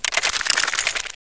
growlf1.wav